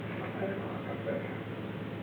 Secret White House Tapes
Conversation No. 442-72
Location: Executive Office Building
The President met with an unknown man.